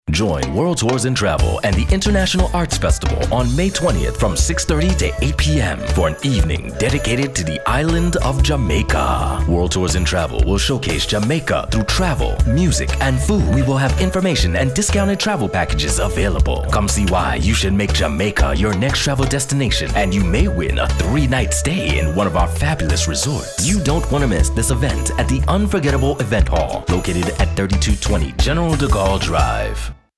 Strong, Versatile, African American, Urban, Caucasian, Caribbean, New York. Professional, Smooth.
mid-atlantic
Sprechprobe: eLearning (Muttersprache):